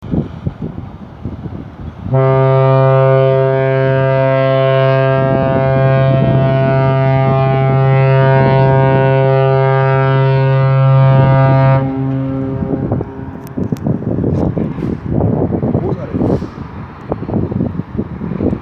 Typhon-HLM Hamburg